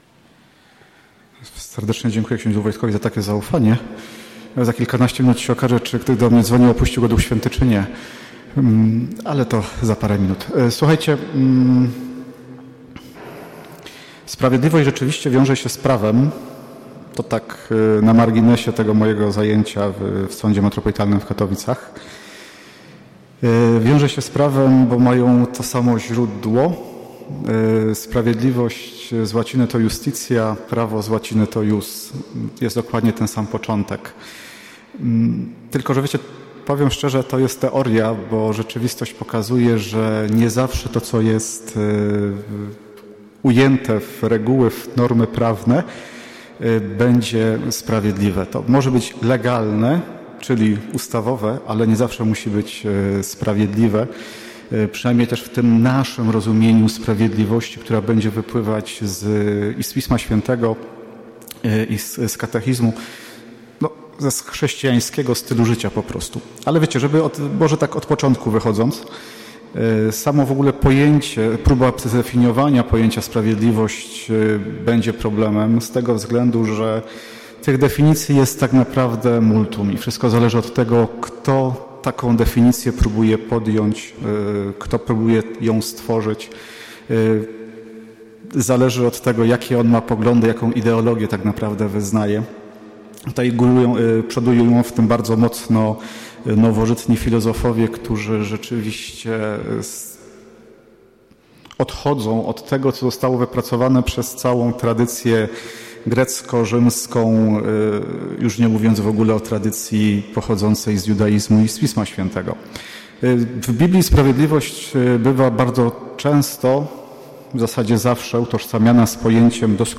Konferencja